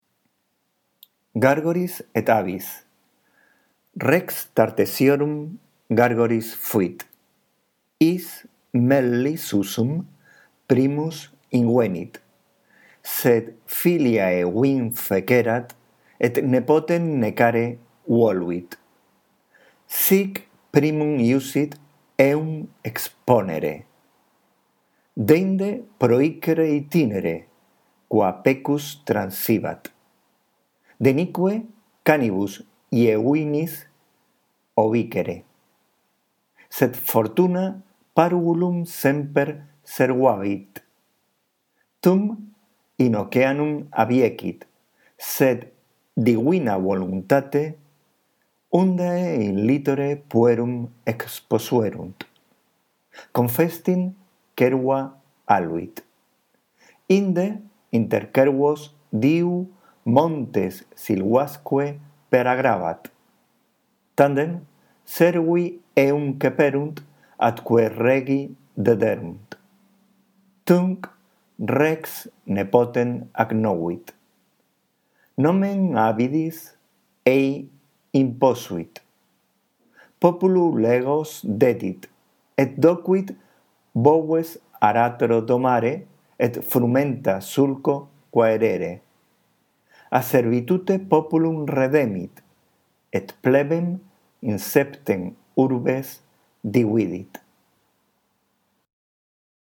La audición de este archivo contribuirá a que mejores tu lectura del latín: